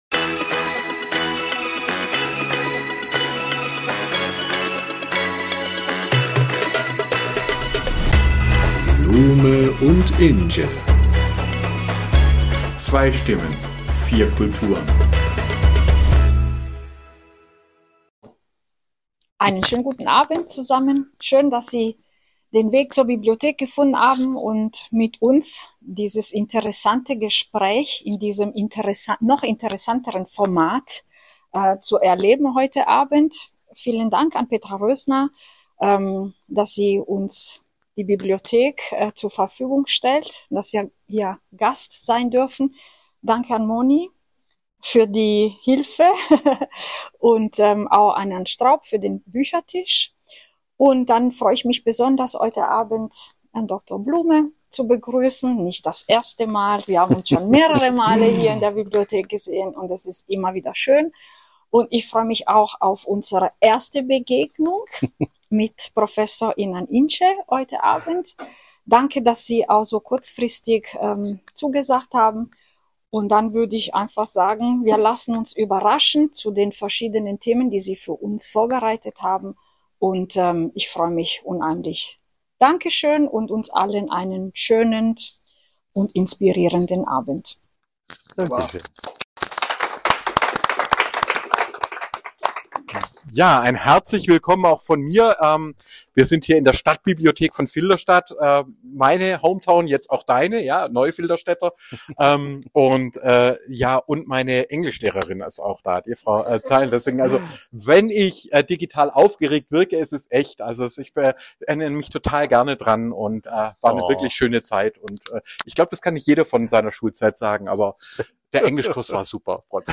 Eine Aufzeichnung aus der Stadtbibliothek Filderstadt.